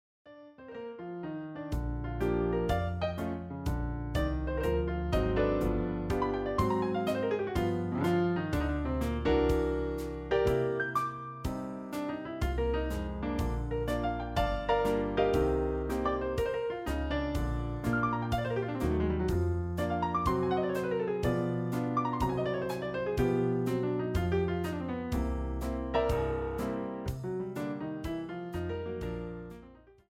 MIDI arrangement for Piano, Bass and Drums
Piano Channel 1
Bass Channel 5
Drums Channel 10